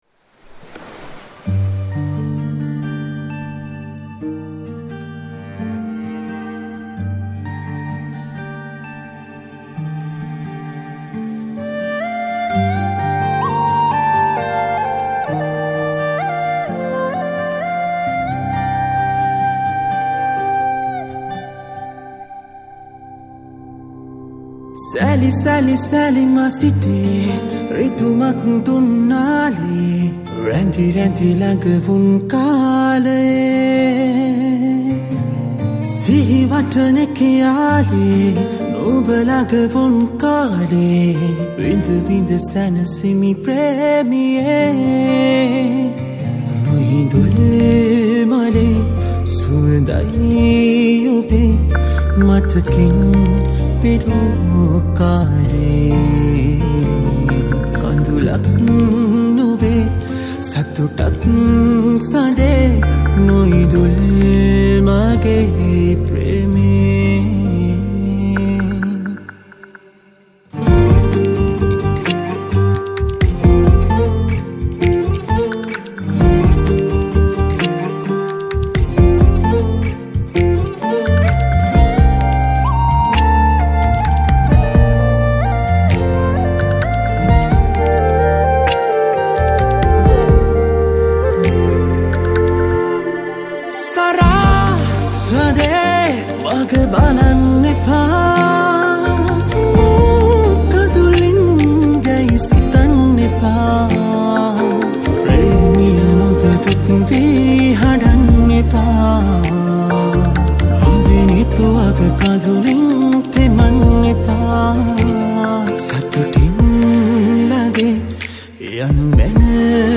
Guitar
Flute